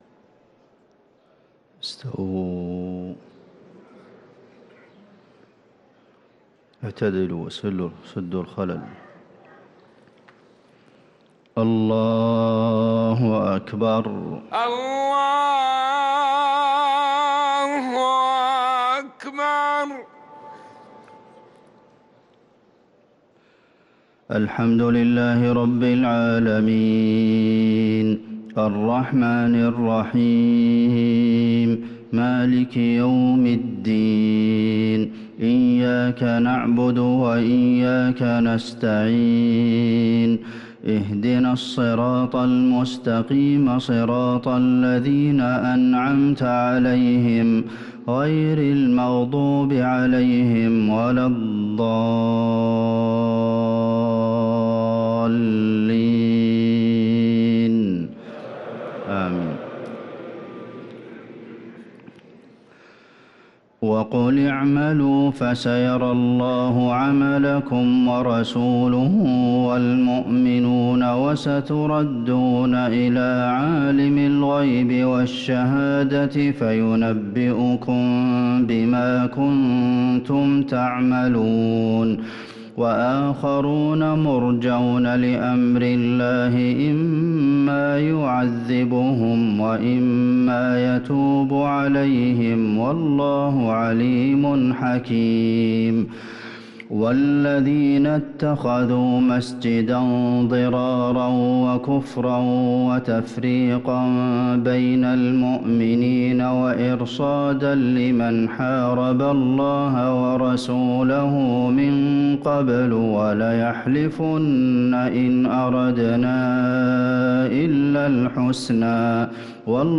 صلاة الفجر للقارئ عبدالمحسن القاسم 16 رمضان 1444 هـ
تِلَاوَات الْحَرَمَيْن .